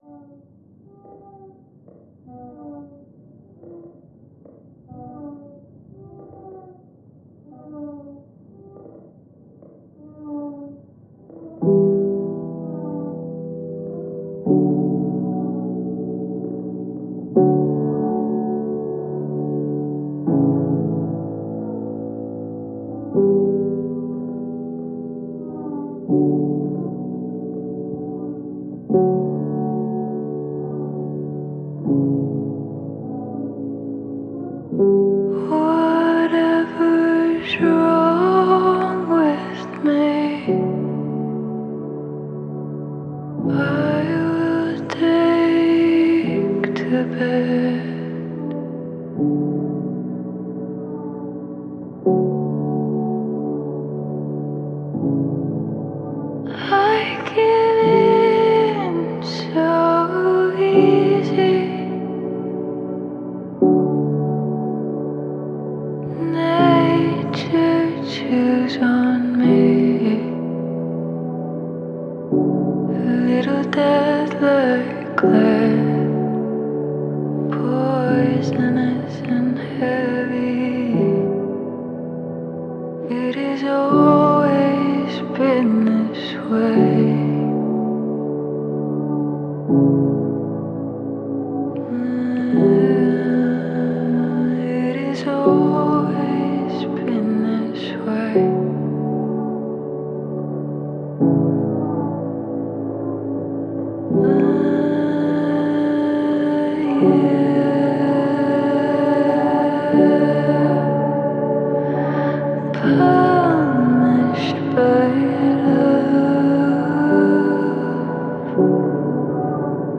ملودی آهنگ بسیار آروم هست